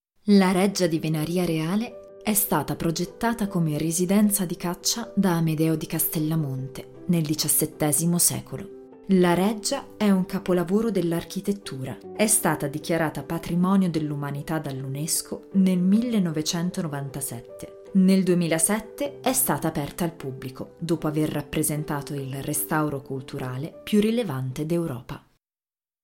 Comercial, Profundo, Versátil, Amable, Cálida
Audioguía